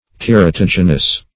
Search Result for " keratogenous" : The Collaborative International Dictionary of English v.0.48: Keratogenous \Ker`a*tog"e*nous\, a. [Gr. ke`ras, -atos, horn + -genous.]
keratogenous.mp3